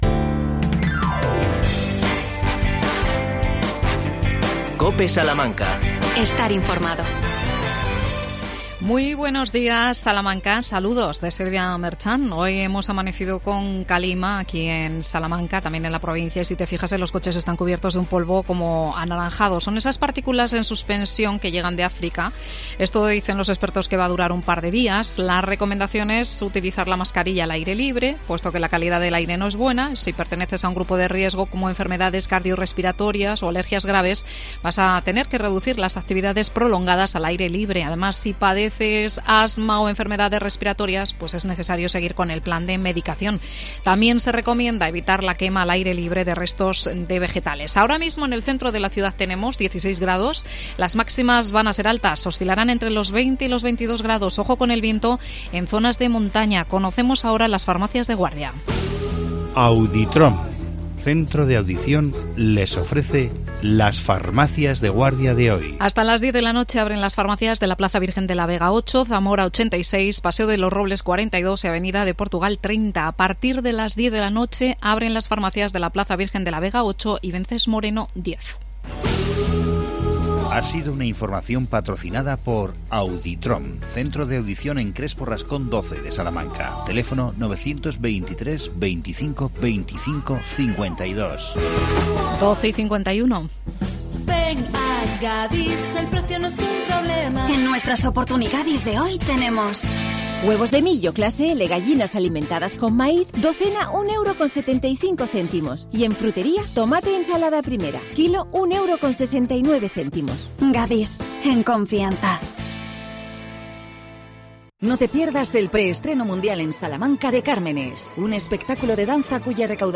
Microespacio Ayuntamiento de Salamanca.Entrevista al concejal de Promoción Económica Juan José Sánchez.